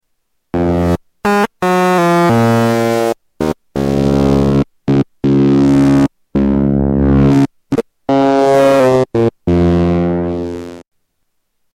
Category: Sound FX   Right: Personal
Tags: Sound Effects EML ElectroComp 101 EML101 ElectroComp 101 Synth Sounds